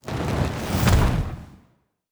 Fire Spelll 22.wav